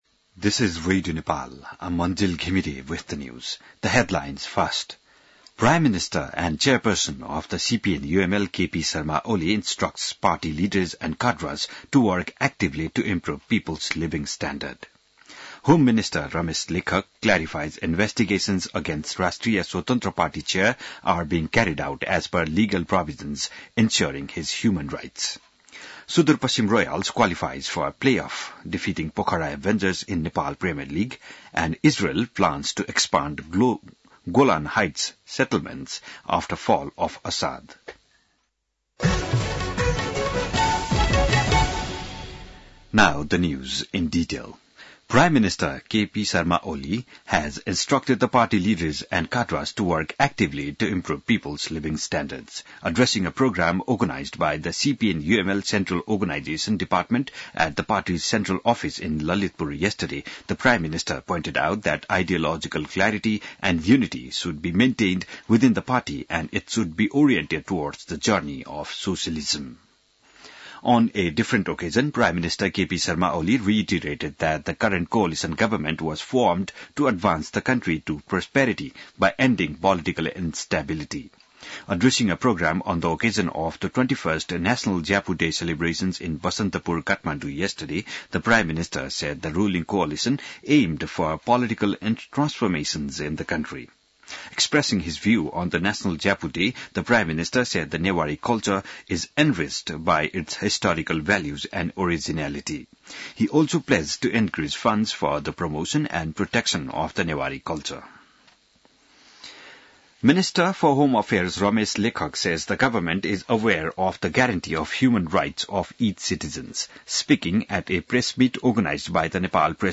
बिहान ८ बजेको अङ्ग्रेजी समाचार : २ पुष , २०८१